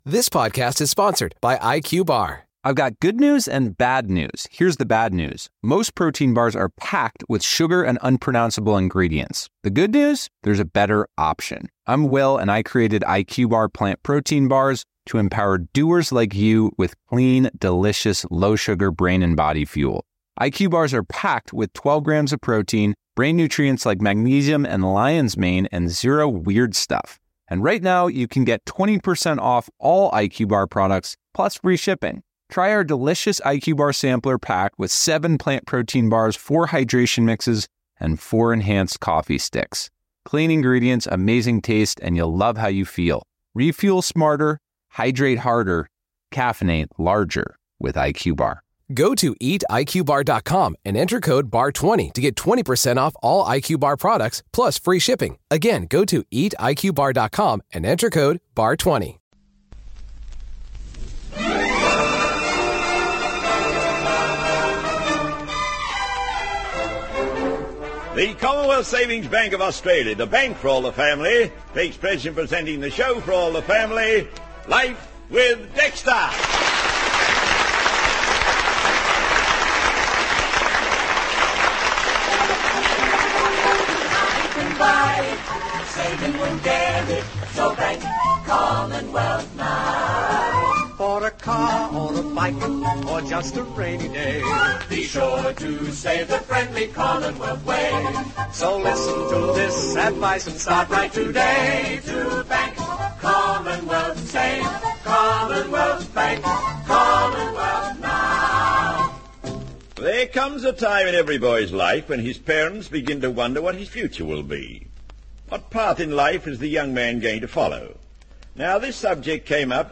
"Life with Dexter" was a popular Australian radio comedy program that aired from the 1950s through to the mid-1960s.